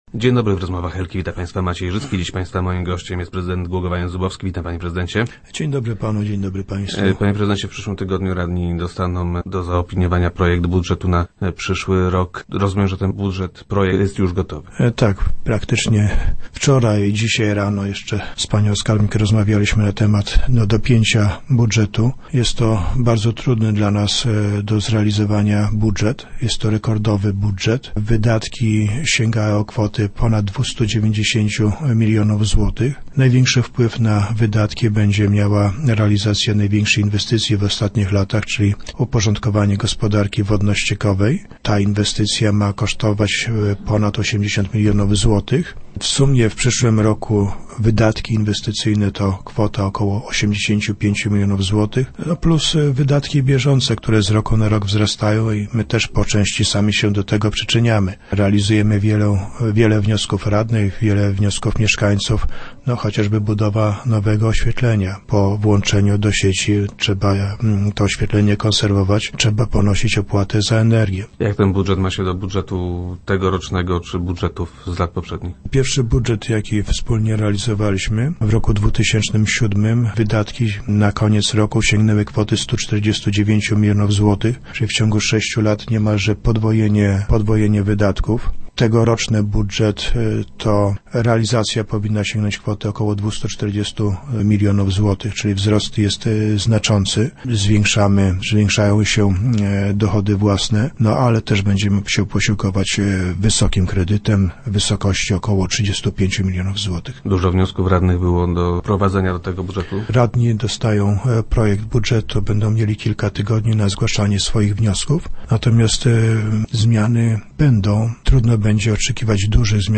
- Jeszcze w środę rano prowadziliśmy ostatnie rozmowy, żeby je dopiąć - powiedział nam Jan Zubowski, prezydent Głogowa, który był gościem Rozmów Elki.